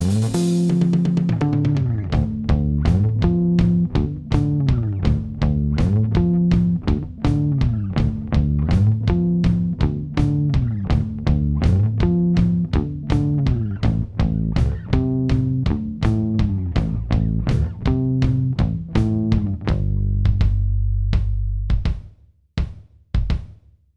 Minus All Guitars Pop (1960s) 4:25 Buy £1.50